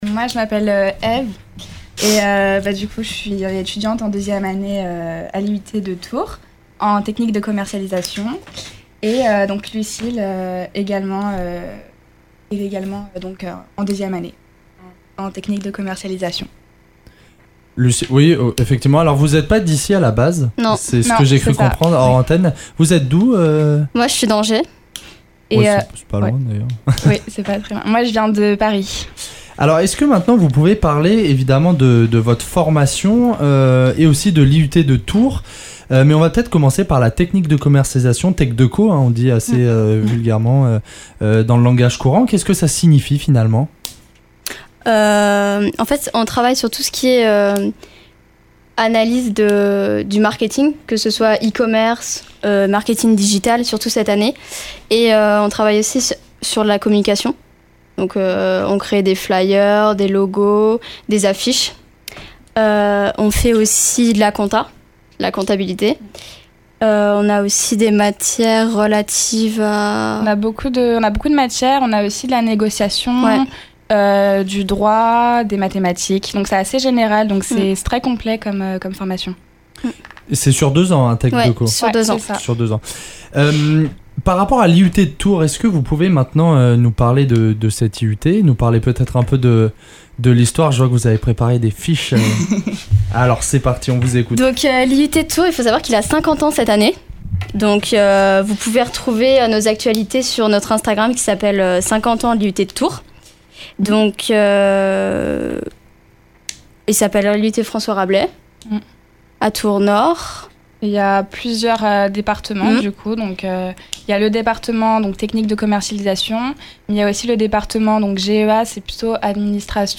Interview !